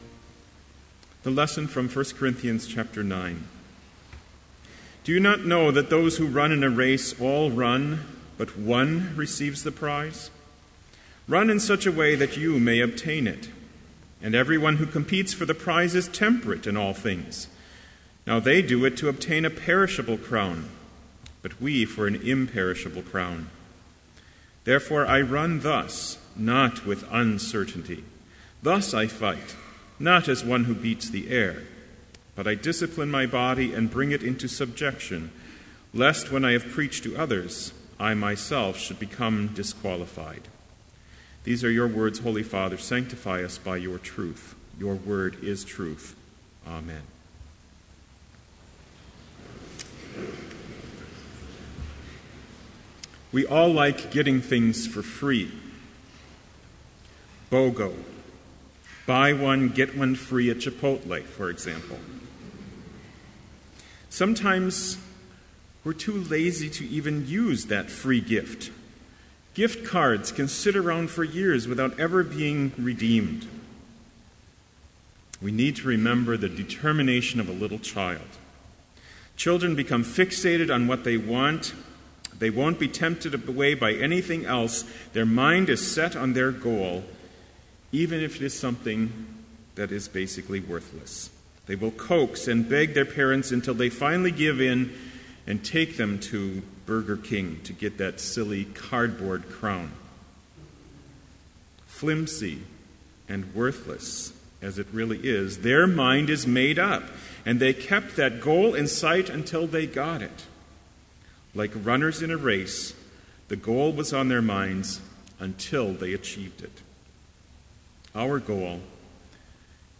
Complete Service
• Hymn 249, vv. 1 & 2, Fight the Good Fight
This Chapel Service was held in Trinity Chapel at Bethany Lutheran College on Wednesday, February 4, 2015, at 10 a.m. Page and hymn numbers are from the Evangelical Lutheran Hymnary.